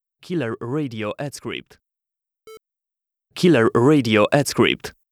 Below is a sample recorded by me on a Shure Beta 58A.